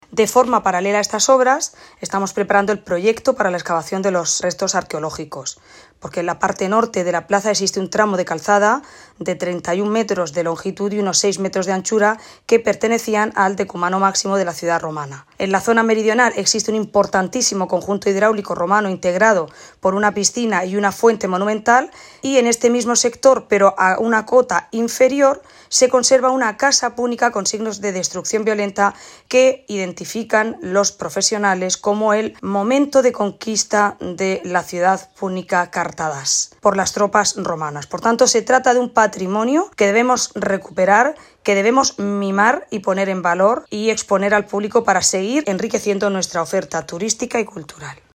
Audio: Declaraciones Ana Bel�n Castej�n obras (MP3 - 1,77 MB)